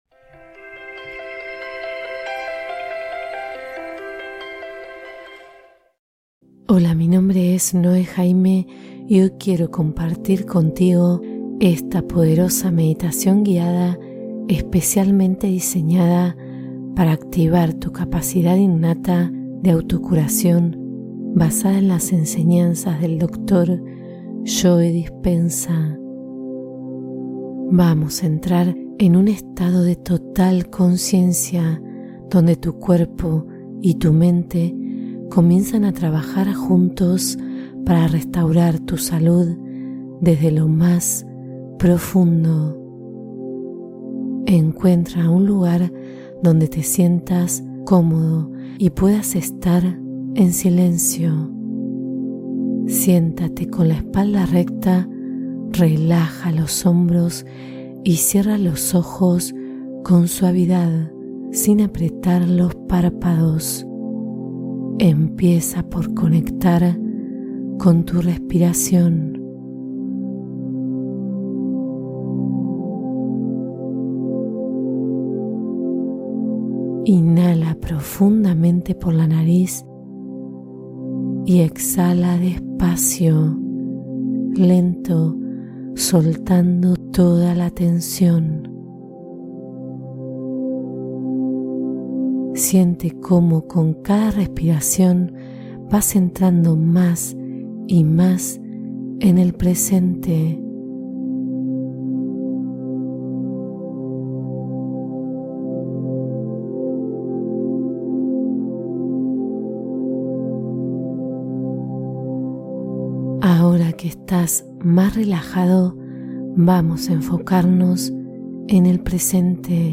Renovación Rápida de Energía: Meditación Curativa Breve